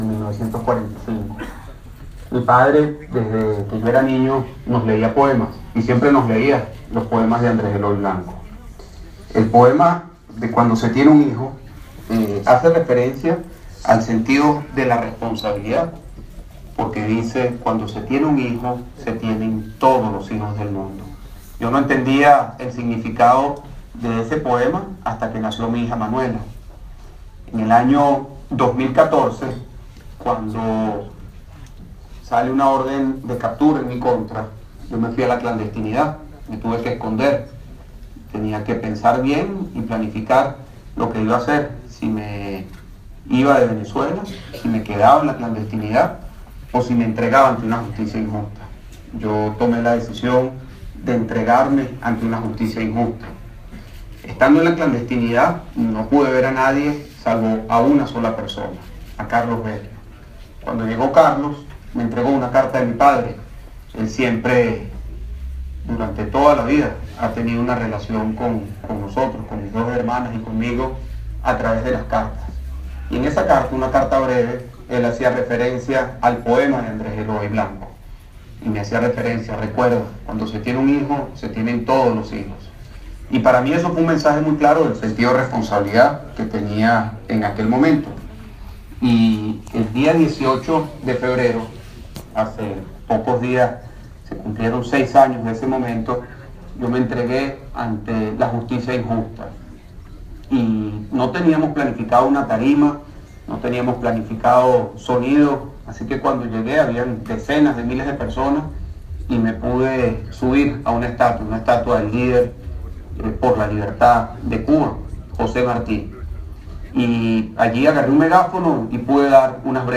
A través de un mensaje de audio, el dirigente de Voluntad Popular ha lanzado un emotivo mensaje a su padre, y ha agradecido a los españoles el trato que siempre han dado a su familia.
A España y a todos los españoles, tengo una palabra, que repito mil veces: gracias", afirma Leopoldo López Mendoza, desde la embajada española en Caracas
Leopoldo López hijo habla sobre la relación con su padre en la presentación del libro 'Cuando se tiene un hijo' .